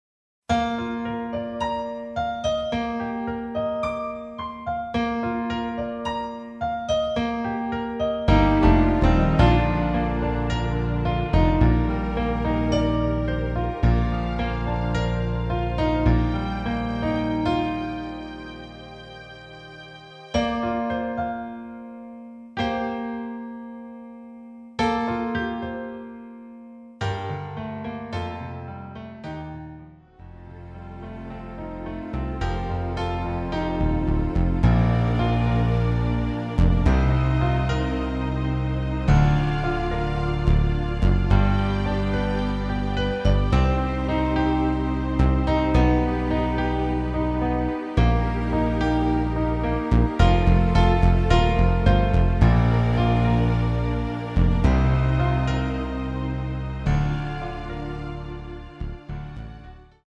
발매일 1995 키 Bb 가수
원곡의 보컬 목소리를 MR에 약하게 넣어서 제작한 MR이며